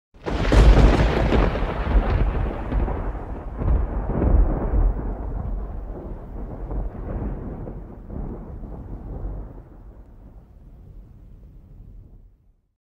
Thunder 3 Sound Effect Free Download
Thunder 3